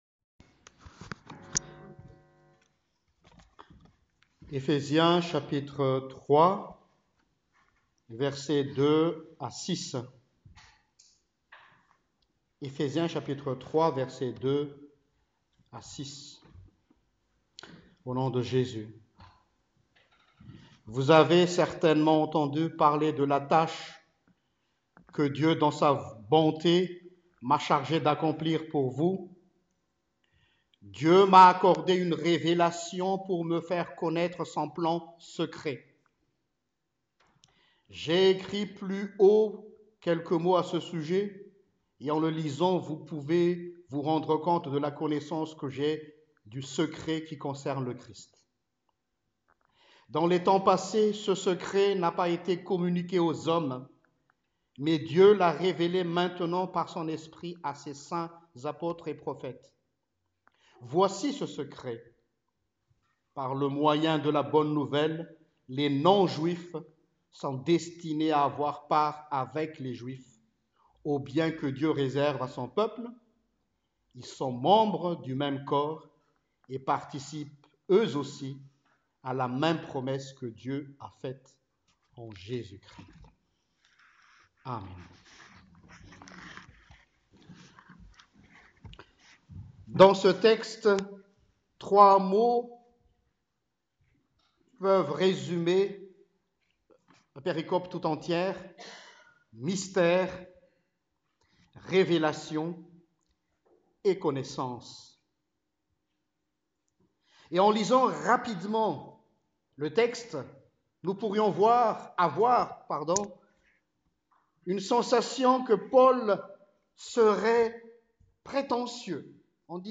Prédication du 06 Janvier: Le Dieu Des Oubliés